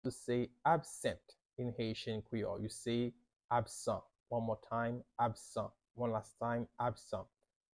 “Absent” means "absan" in Haitian Creole – “Absan” pronunciation by a Haitian Creole teacher
“Absan” Pronunciation in Haitian Creole by a native Haitian can be heard in the audio here or in the video below: